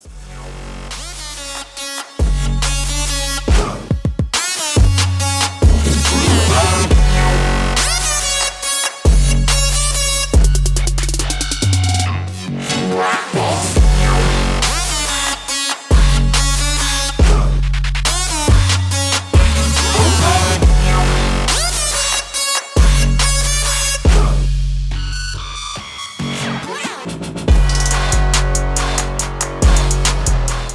Android, Elektronisk musik